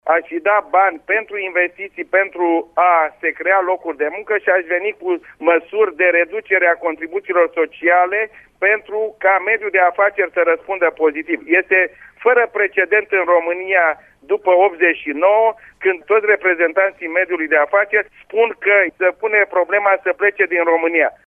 Gheorghe Ialomiţianu, fost ministru al Finanţelor.